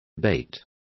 Also find out how ceba is pronounced correctly.